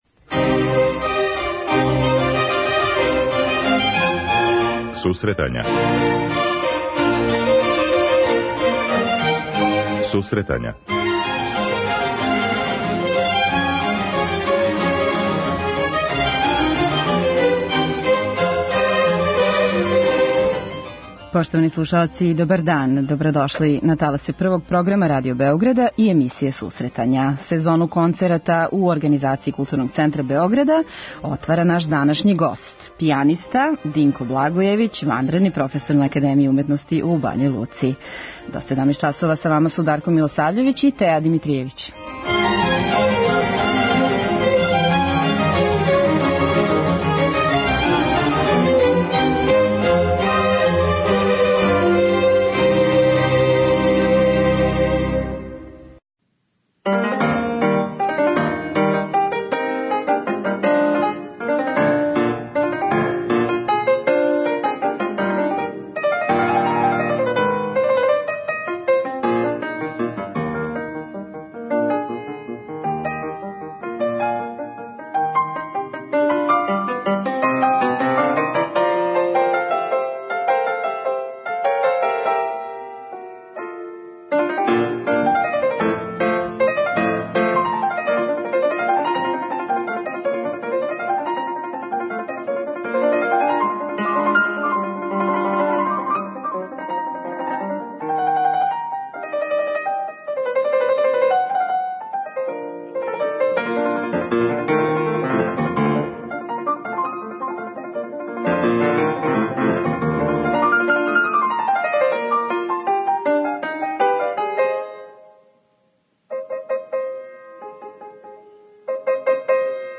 преузми : 10.77 MB Сусретања Autor: Музичка редакција Емисија за оне који воле уметничку музику.